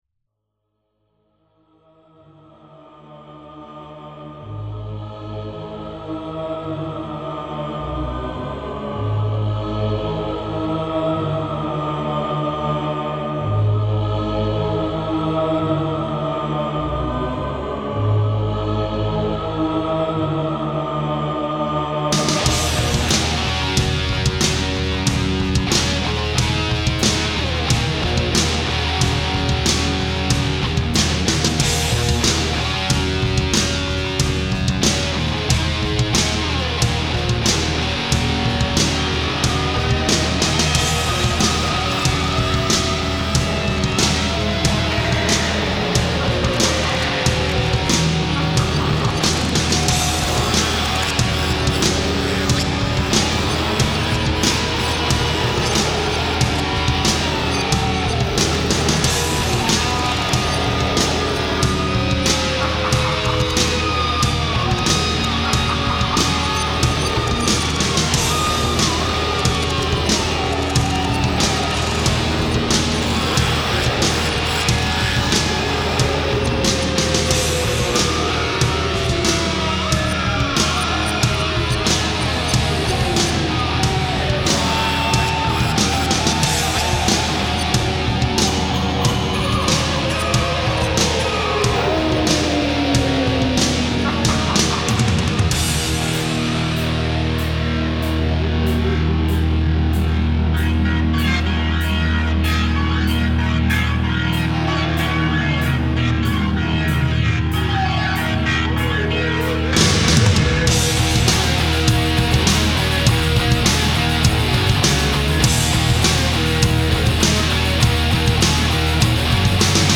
با صدای سوزناک